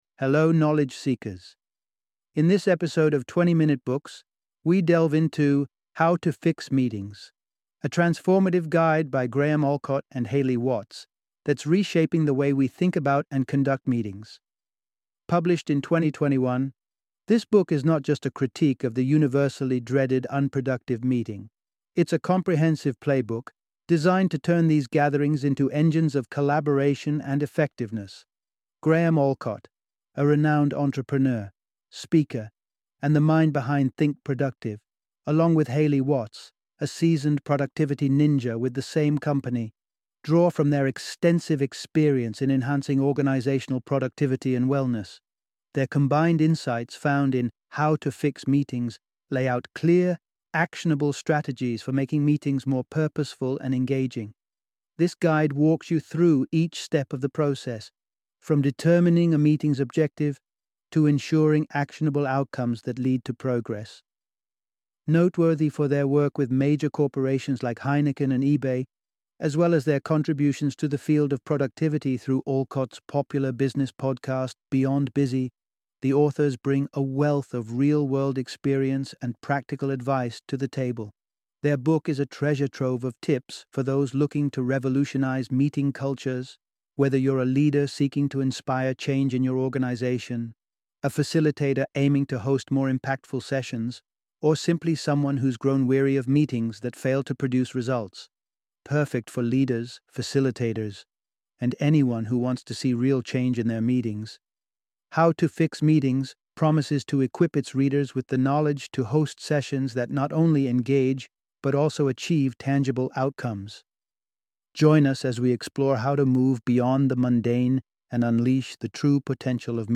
How to Fix Meetings - Audiobook Summary